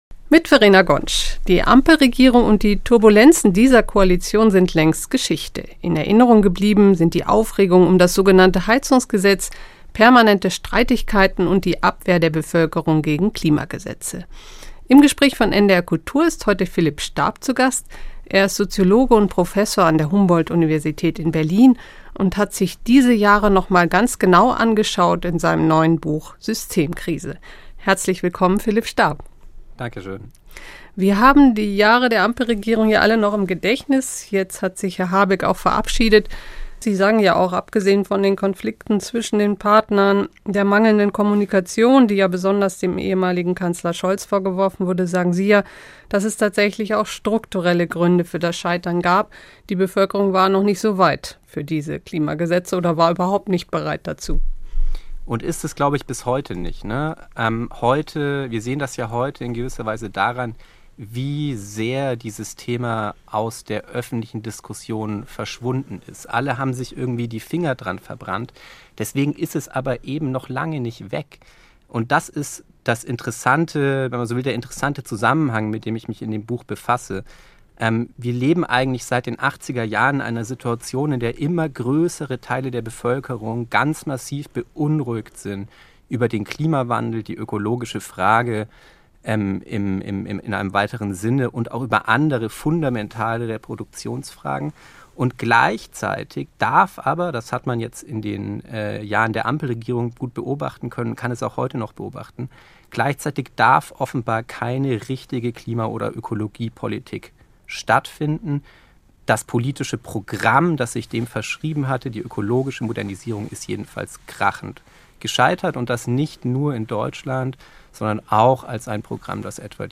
im Gespräch ~ NDR Kultur - Das Gespräch Podcast